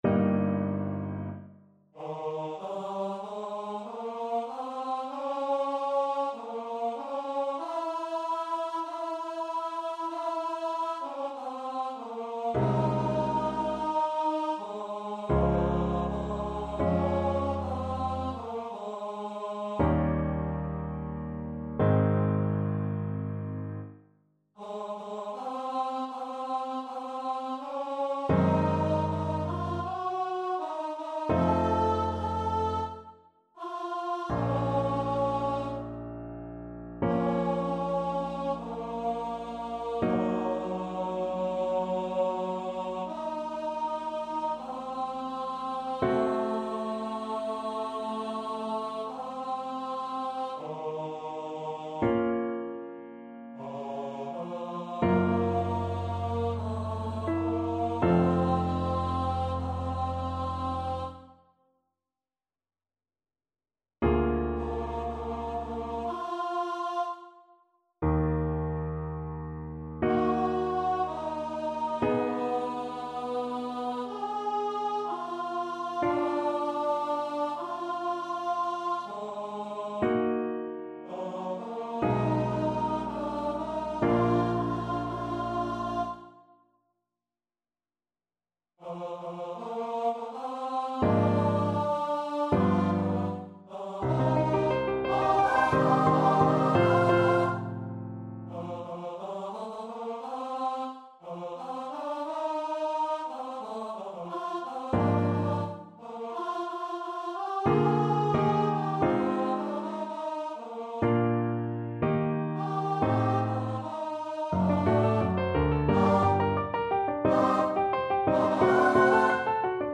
Free Sheet music for Choir
Classical (View more Classical Choir Music)